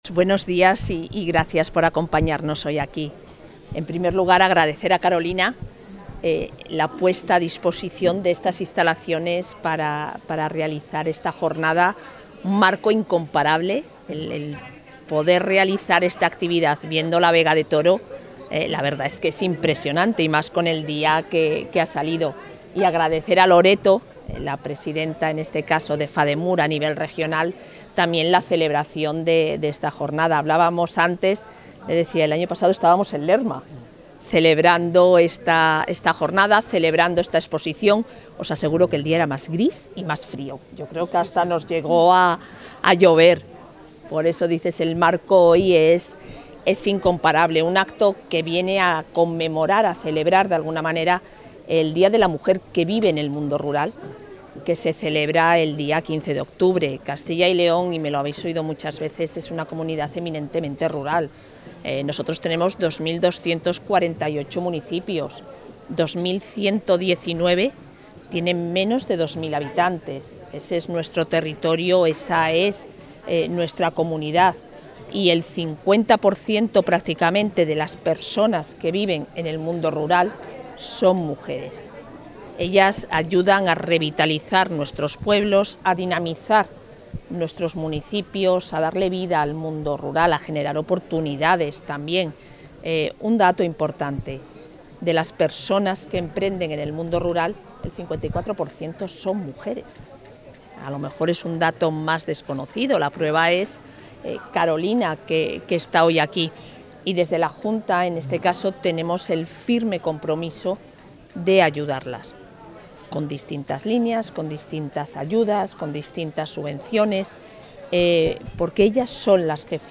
Intervención de la vicepresidenta de la Junta.
La vicepresidenta y consejera de Familia e Igualdad de Oportunidades participa en la bodega Monte la Reina de Toro (Zamora), con motivo del Día de la Mujer Rural, en una jornada organizada por Fademur en la que ha reiterado el apoyo del Ejecutivo autonómico a las mujeres que emprenden y se desarrollan en los pequeños núcleos rurales de Castilla y León y ha reivindicado su papel esencial como motor económico.